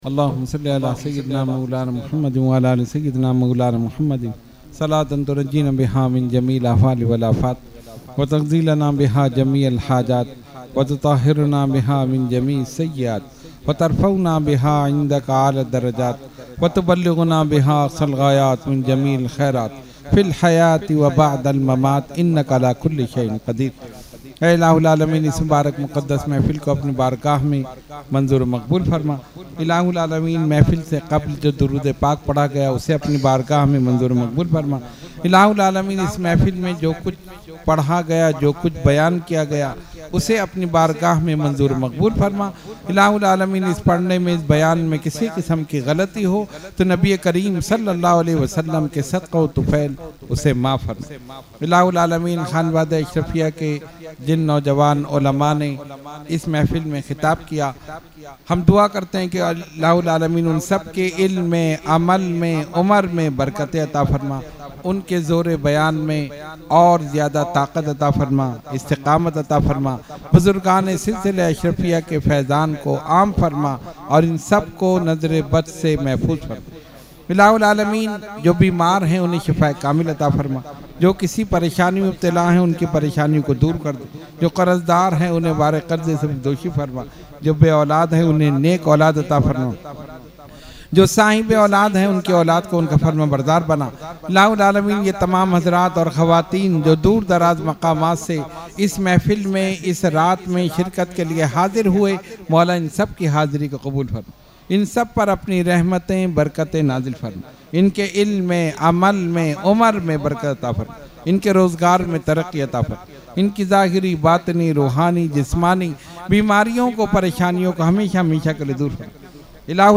Dua – Subah Baharan 2024 – Dargah Alia Ashrafia Karachi Pakistan
Mehfil e Jashne Subhe Baharan held on 16 September 2024 at Dargah Alia Ashrafia Ashrafabad Firdous Colony Gulbahar Karachi.